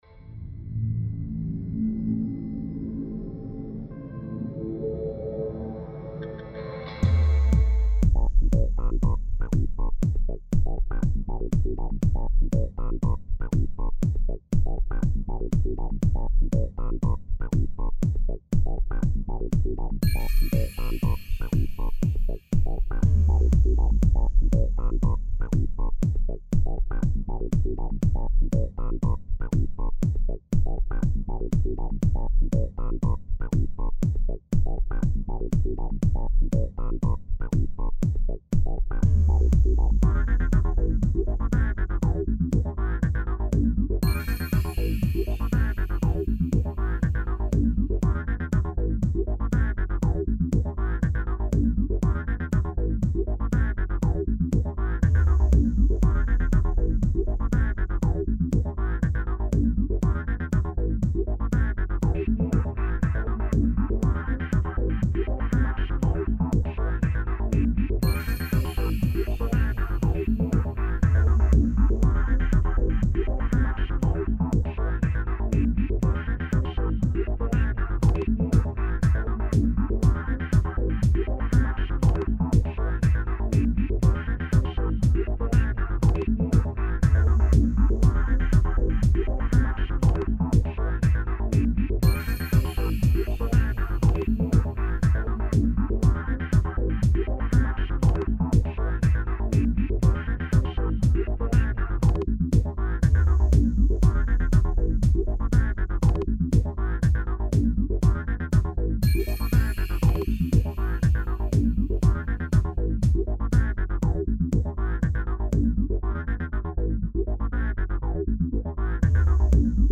I sometimes write techno songs under the band name "The Pure Virtual Method".
Mellow Beat Drop The oldest of my songs, it's traditional techno.